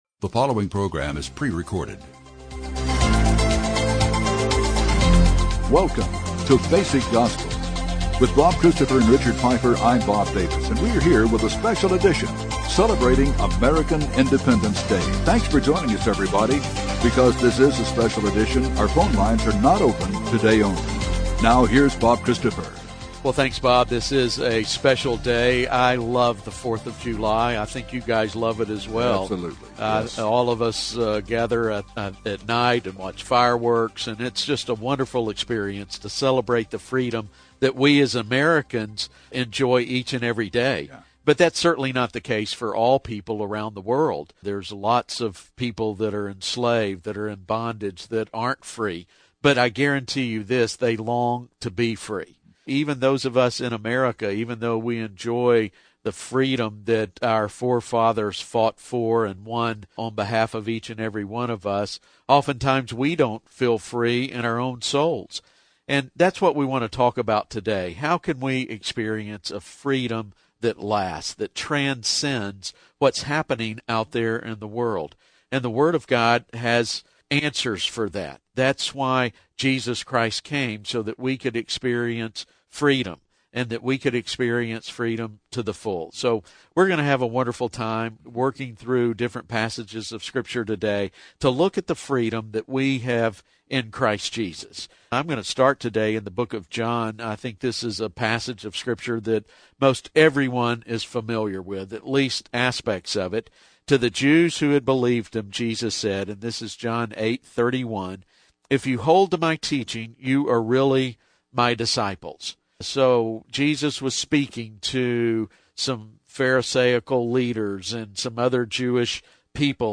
A special Independence Day broadcast from Basic Gospel.